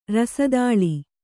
♪ rasa dāḷi